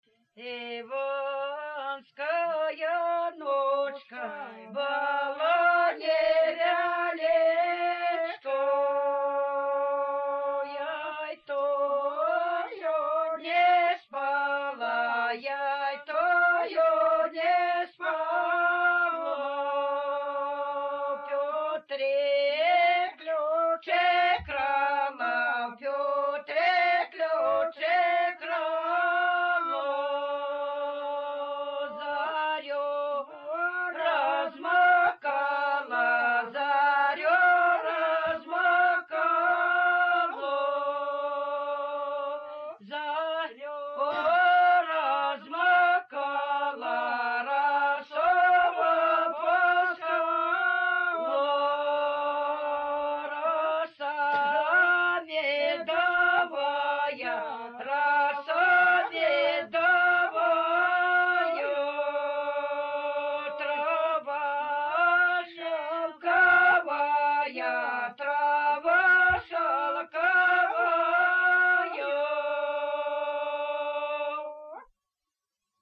Купальные обрядовые песни Невельского района